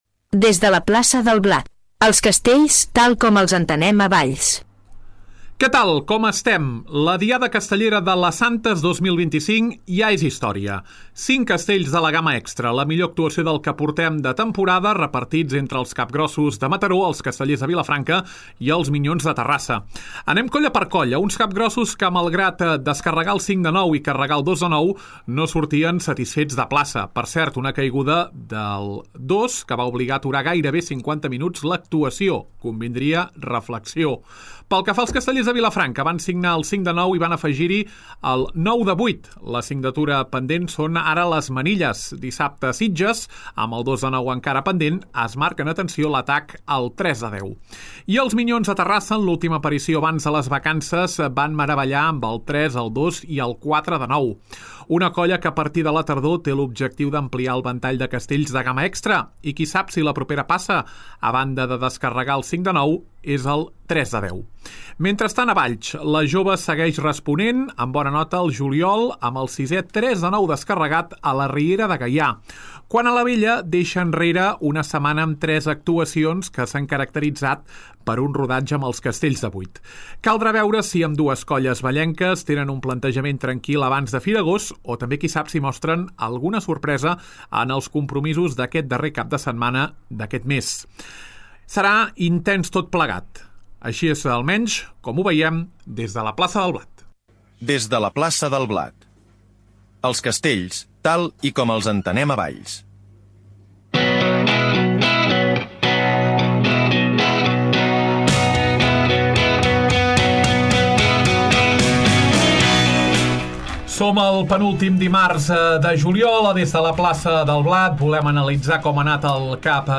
Sisena edició de Des de la Plaça del Blat, el programa que explica els castells tal com els entenem a Valls. Tertúlia amb l’anàlisi de les diades de la Riera de Gaià